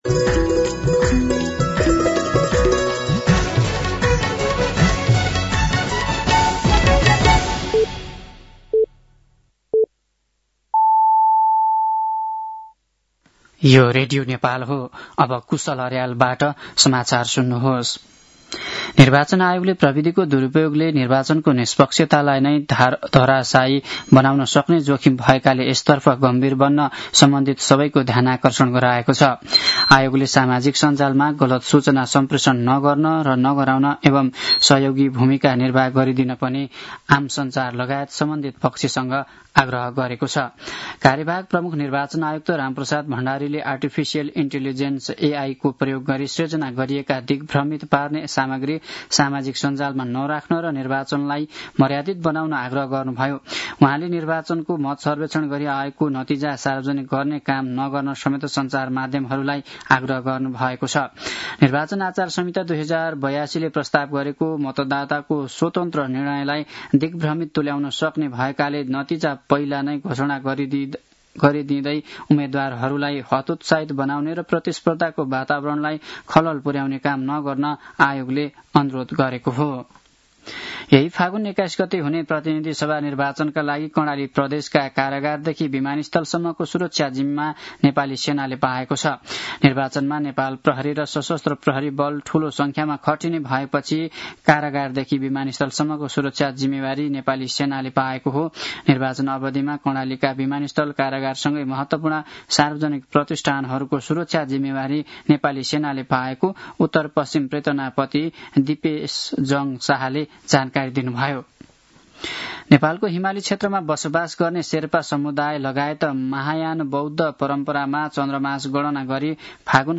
साँझ ५ बजेको नेपाली समाचार : ६ फागुन , २०८२